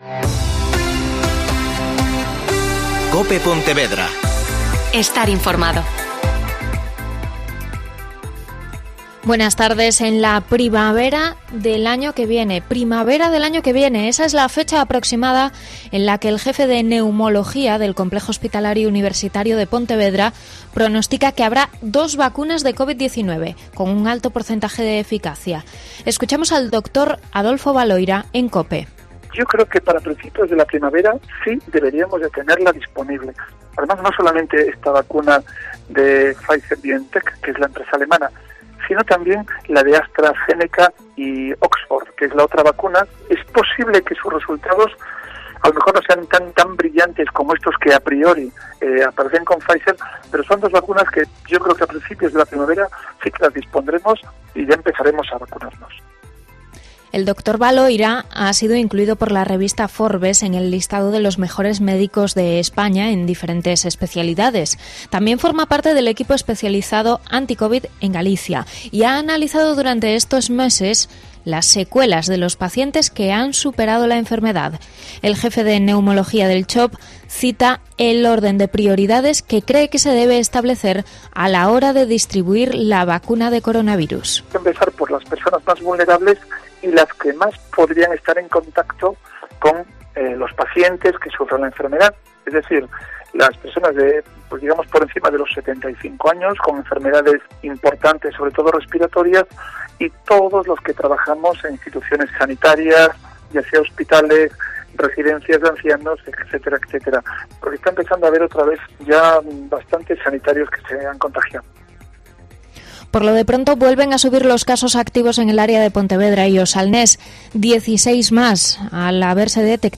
Mediodía COPE Pontevedra (Informativo 14.20h)